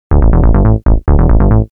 SNTHBASS146_TEKNO_140_A_SC2.wav